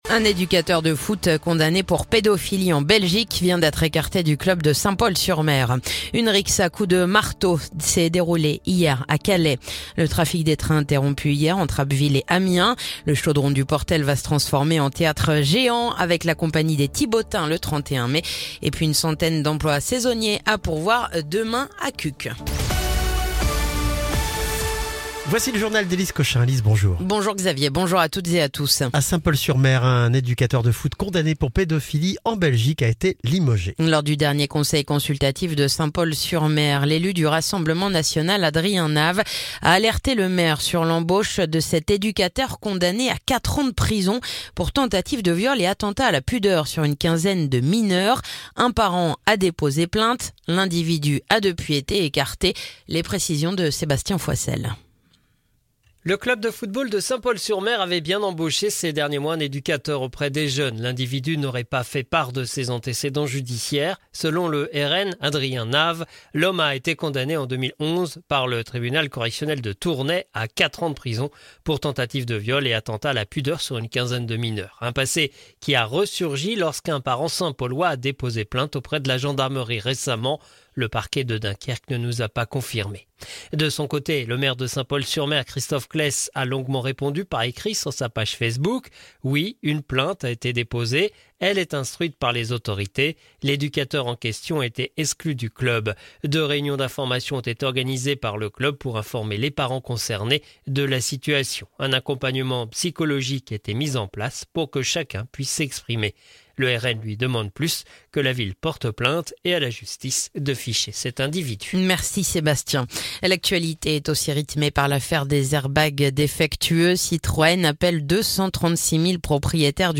Le journal du mardi 18 février